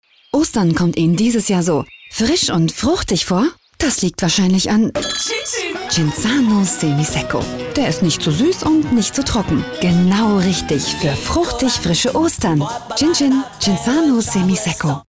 Deutsche Sprecherin für Funkspots, TV-Spots, Industriefilm, Warteschleife, Anrufbeantworter. Stimmlage: mittel, weich Stimmalter: 25-40
Sprechprobe: Werbung (Muttersprache):